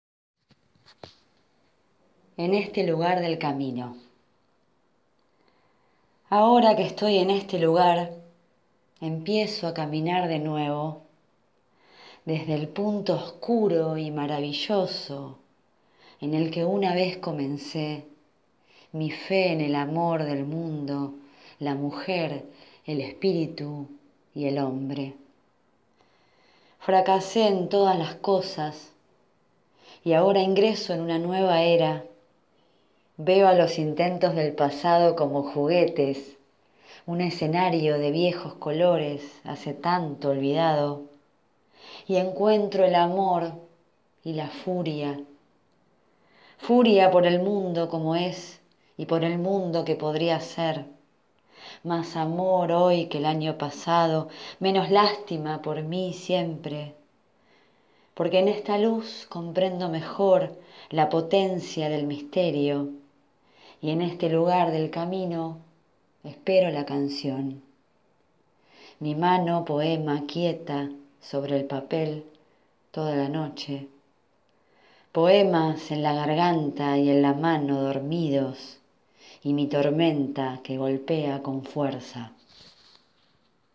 leído